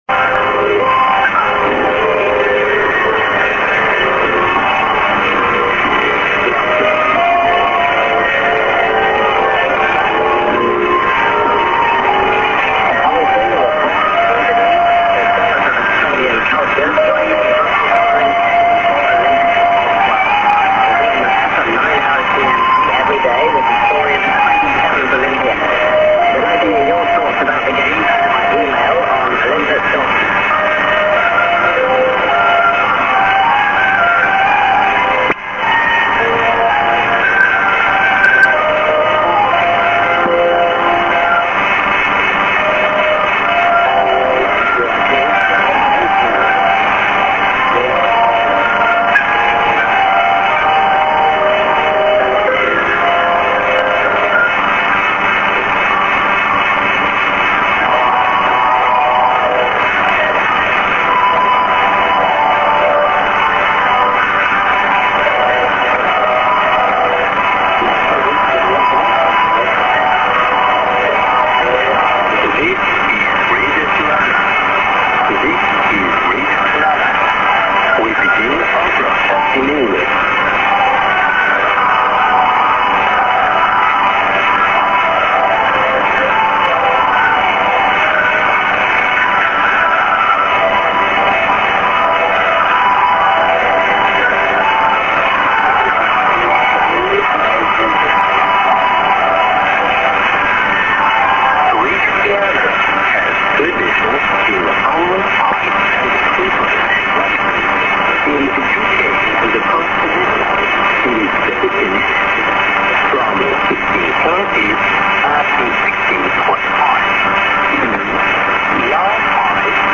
St. IS->ID:"This is R. Tiranax2+SKJ(man)->ID(women)->　＊back TWR Africa 29'30":BBC s/off